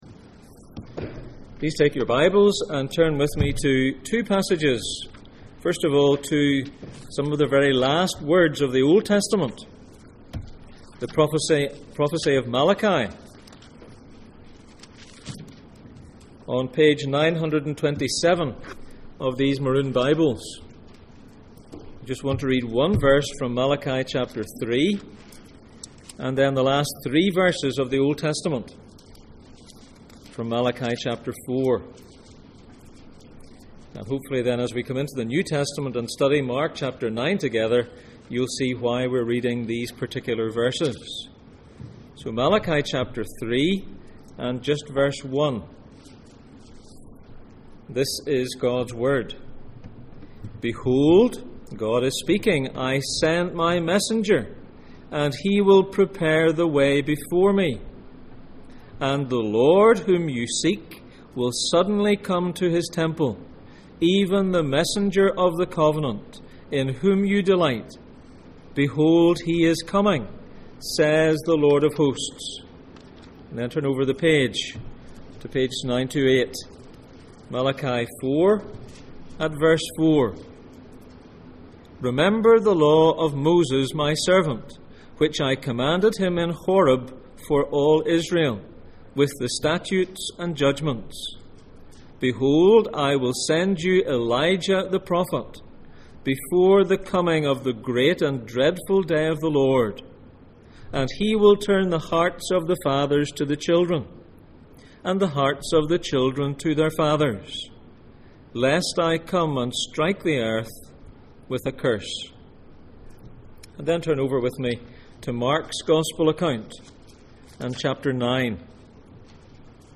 Jesus in Mark Passage: Mark 9:1-13, Malachi 3:1, Malachi 4:4-6, Luke 1:17 Service Type: Sunday Morning